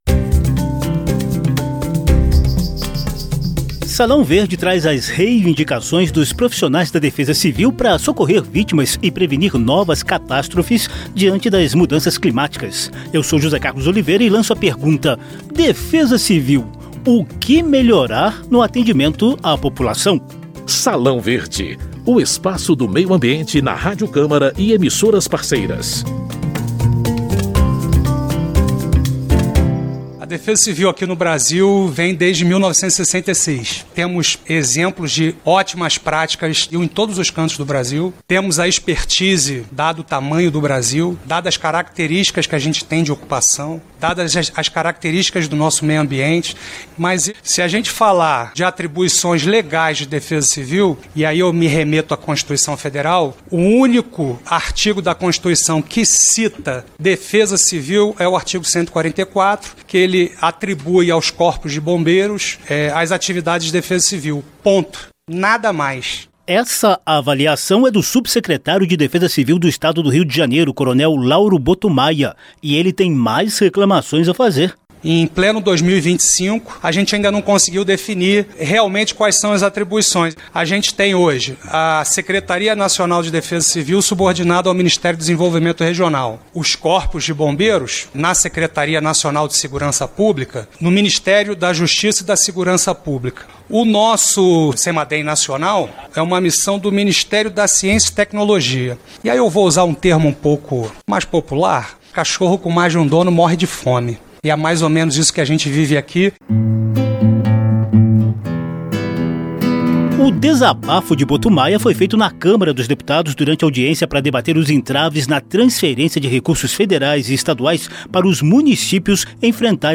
Salão Verde dá voz aos coordenadores de Defesa Civil federal e estaduais na busca de melhoria das operações em situação de emergência e estado de calamidade pública, cada vez mais comuns diante das mudanças climáticas. Há cobrança por mais recursos orçamentários, menos burocracia na legislação e reconhecimento dos agentes de defesa civil como carreira do serviço público. Em debate na Comissão Especial da Câmara dos Deputados sobre Prevenção a Desastres Naturais, eles deram exemplos práticos da burocracia e do corte de recursos que emperram o socorro às vítimas e aos municípios durante eventos extremos de enchentes e secas.